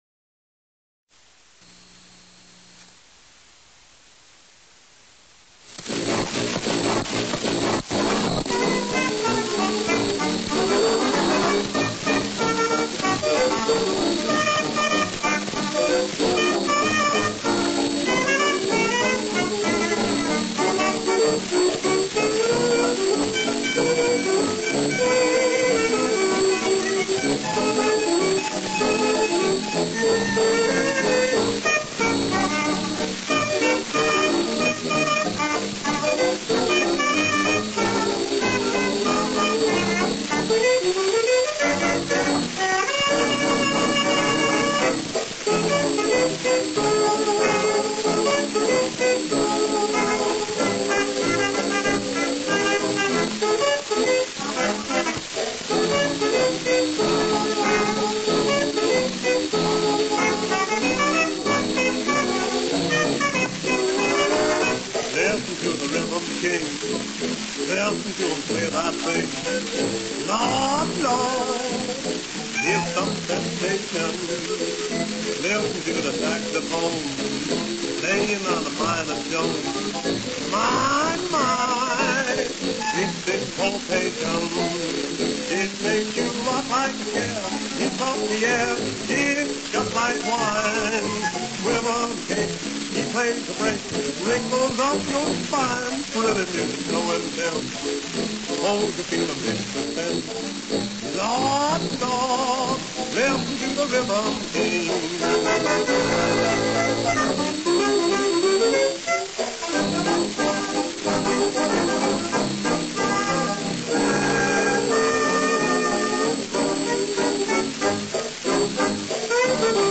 Sorry this is a poor copy.